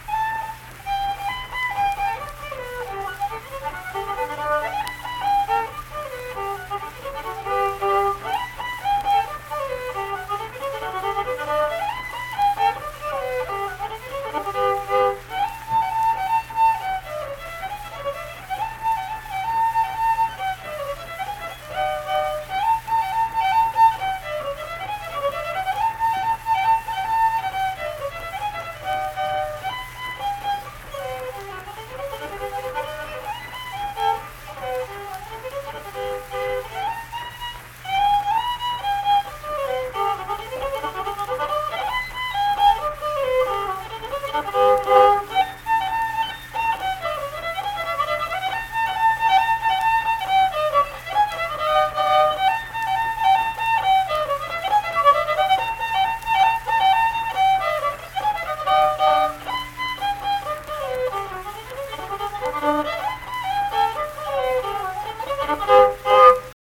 Lop Eared Mule - West Virginia Folk Music | WVU Libraries
Unaccompanied fiddle music performance
Instrumental Music
Fiddle